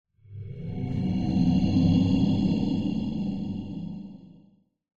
Minecraft Version Minecraft Version snapshot Latest Release | Latest Snapshot snapshot / assets / minecraft / sounds / ambient / cave / cave8.ogg Compare With Compare With Latest Release | Latest Snapshot
cave8.ogg